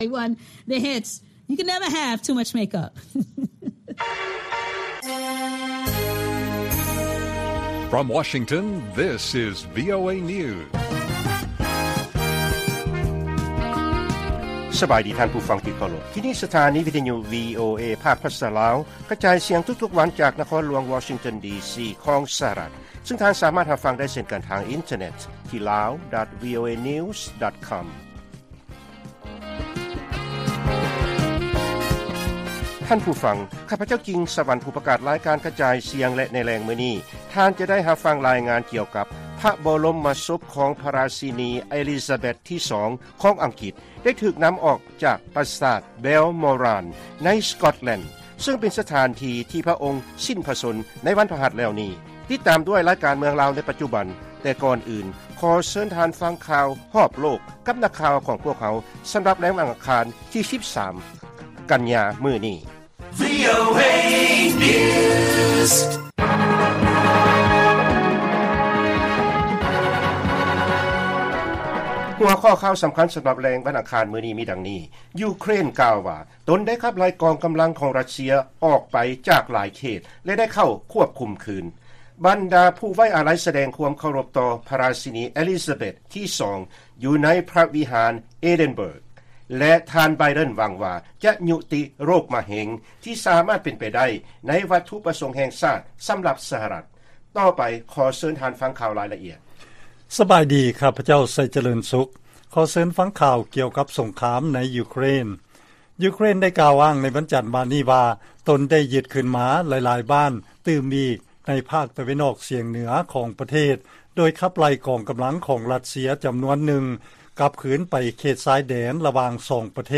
ລາຍການກະຈາຍສຽງຂອງວີໂອເອ ລາວ: ຢູເຄຣນ ກ່າວວ່າ ຕົນໄດ້ຂັບໄລ່ ກອງກຳລັງຣັດເຊຍ ອອກໄປຈາກຫຼາຍເຂດ ແລະໄດ້ເຂົ້າຄວບຄຸມຄືນ